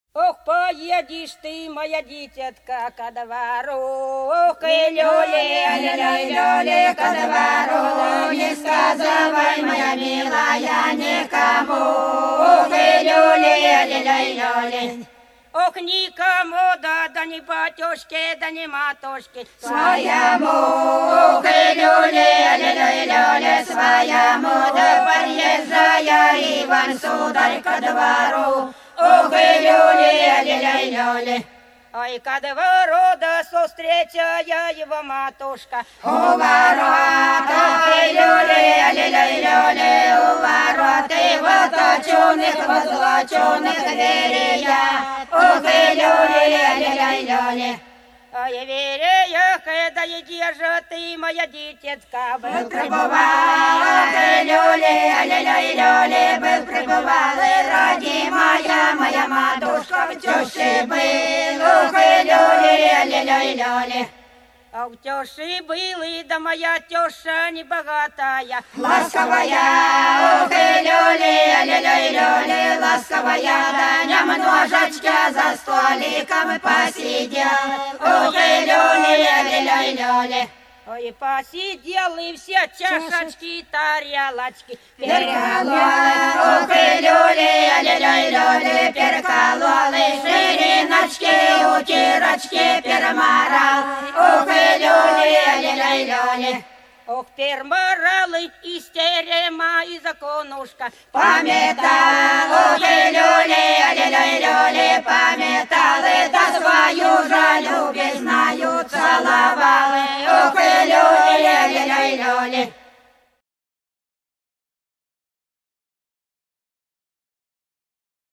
Вдоль по улице пройду (Бутырки Репьёвка) 012. Ох, поедешь ты, мое дитятко ко двору — свадебная песня.